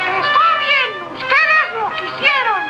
Worms speechbanks
Fireball.wav